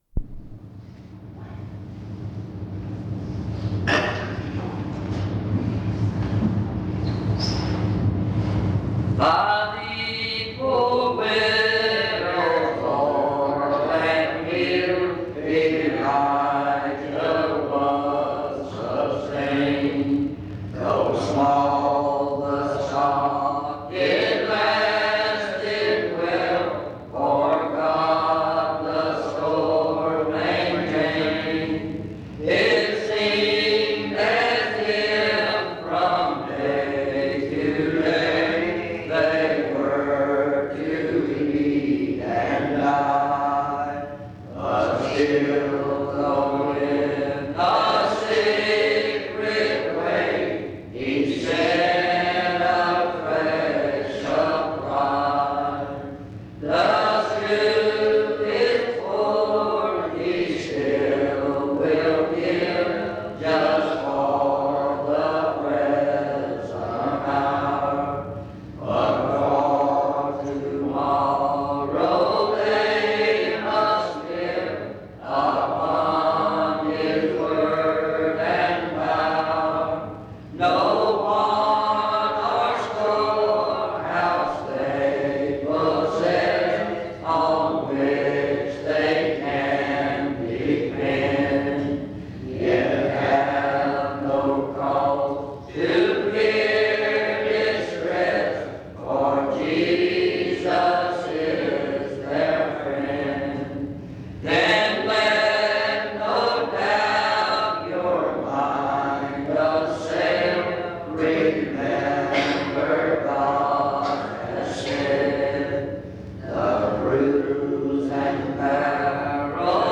In Collection: Reidsville/Lindsey Street Primitive Baptist Church audio recordings Thumbnail Titolo Data caricata Visibilità Azioni PBHLA-ACC.001_015-B-01.wav 2026-02-12 Scaricare PBHLA-ACC.001_015-A-01.wav 2026-02-12 Scaricare